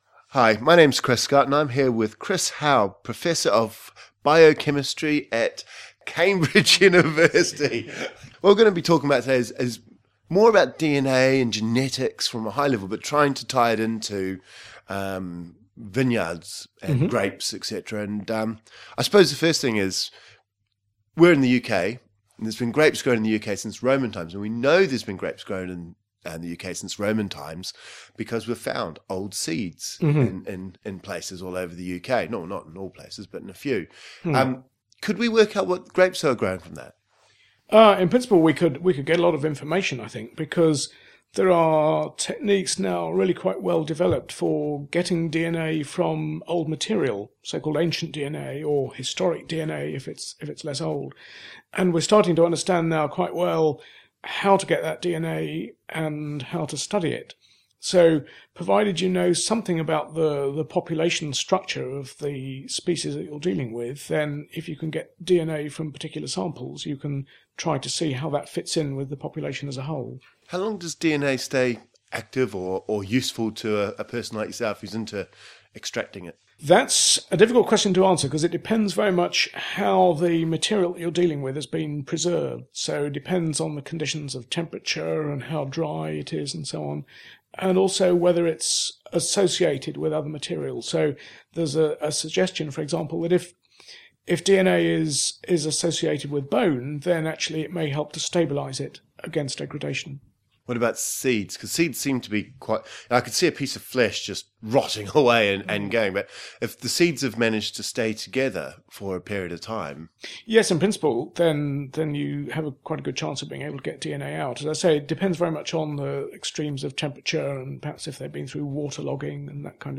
In this interview we find out about DNA and genetics.